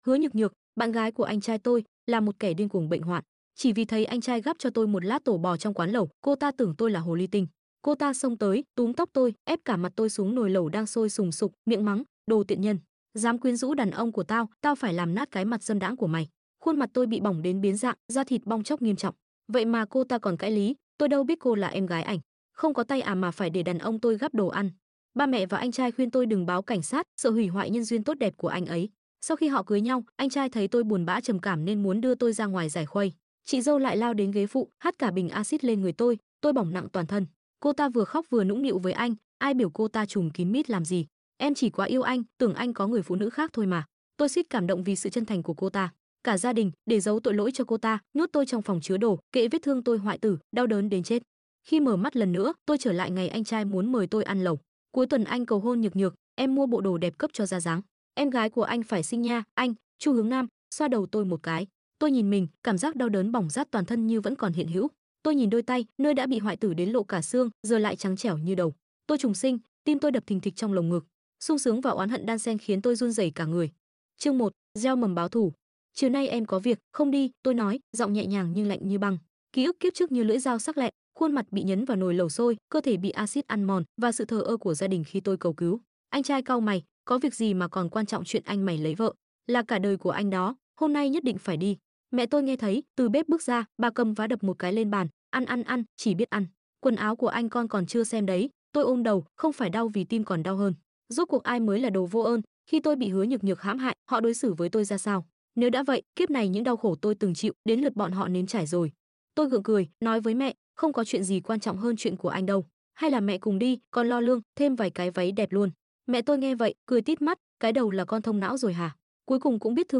TRUYỆN AUDIO|| NGỌN LỬA OÁN sound effects free download